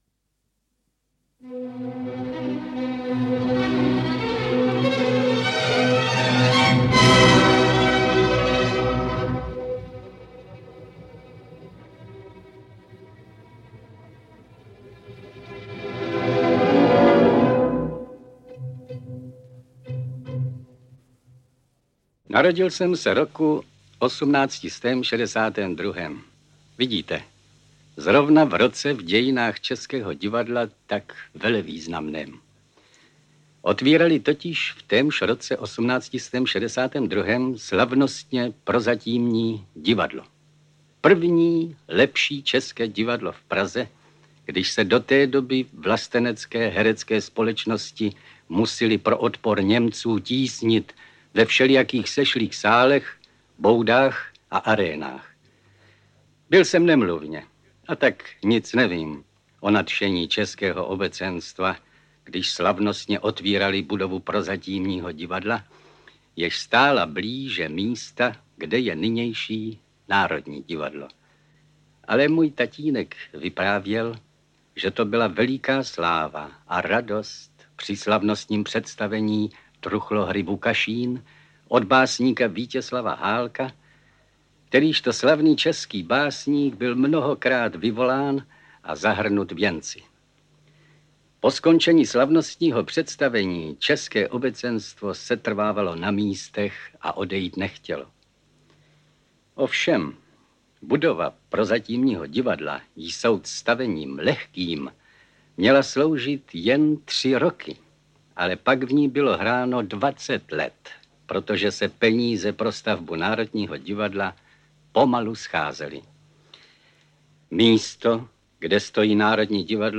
Interpret:  František Smolík
AudioKniha ke stažení, 1 x mp3, délka 49 min., velikost 44,7 MB, česky